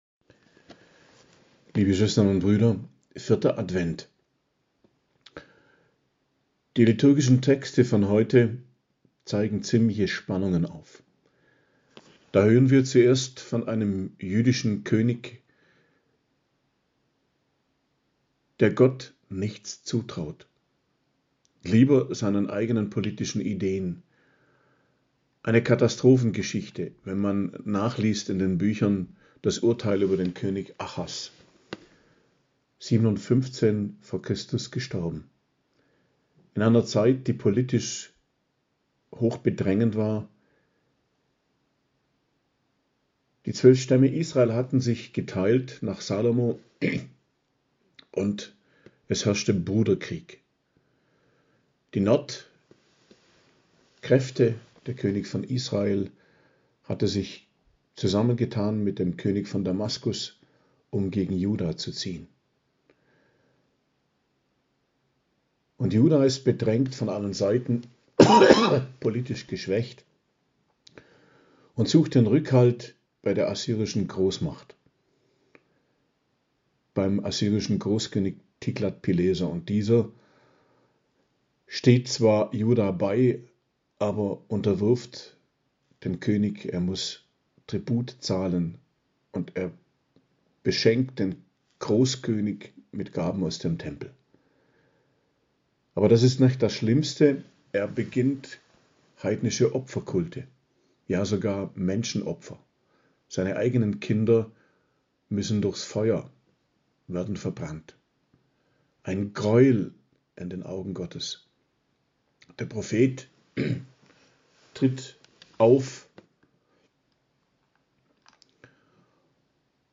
Predigt zum 4. Adventssonntag, 18.12.2022 ~ Geistliches Zentrum Kloster Heiligkreuztal Podcast